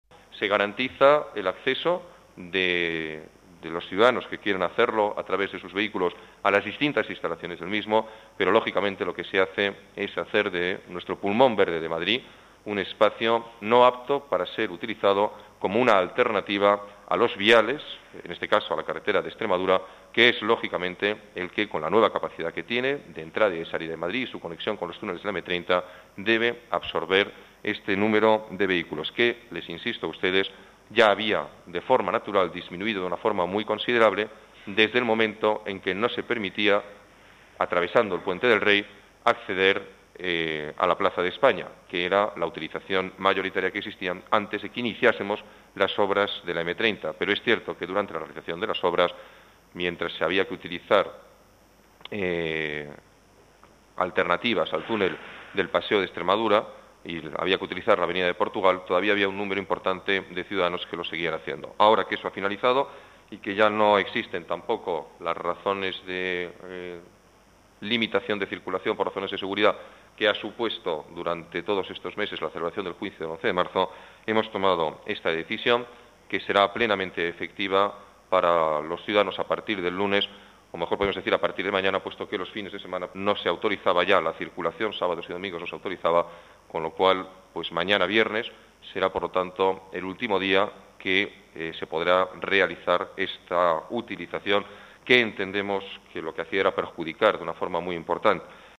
Nueva ventana:Alberto Ruiz Gallardón explica los motivos por los que se ha decidido el cierre de la Casa de Campo al tráfico privado